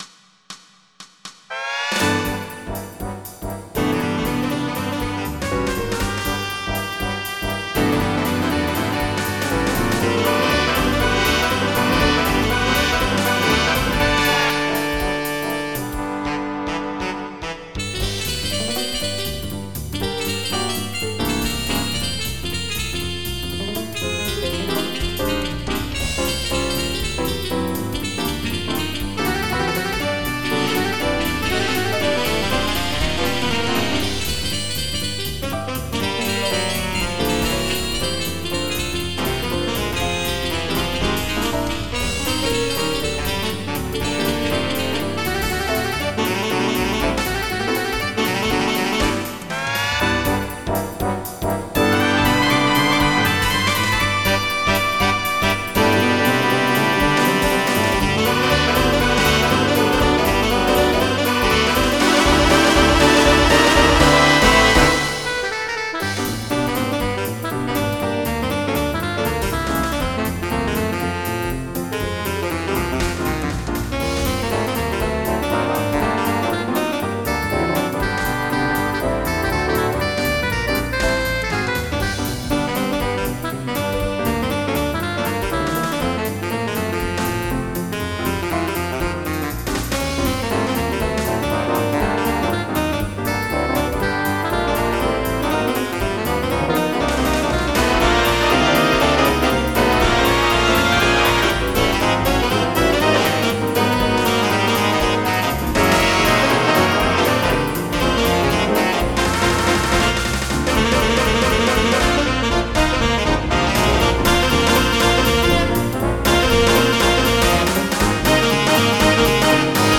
Jazz
MIDI Music File
Type General MIDI